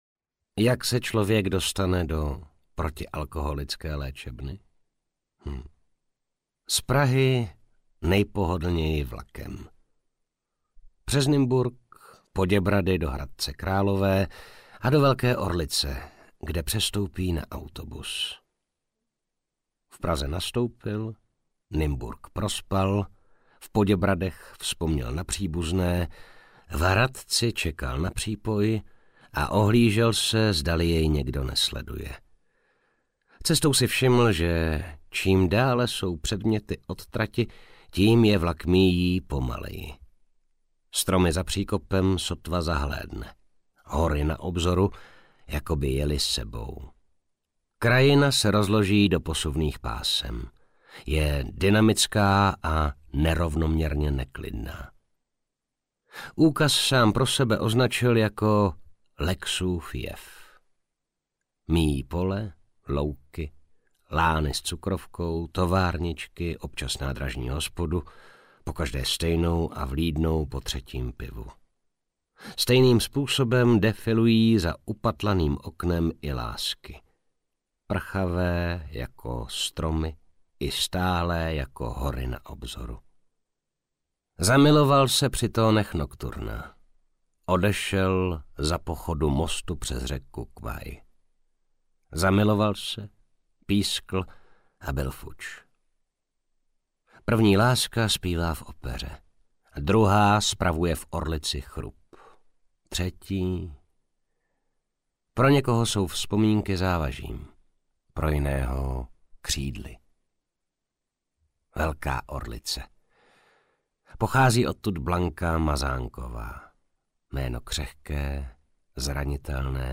Ukázka z knihy
dobri-holubi-se-vraceji-audiokniha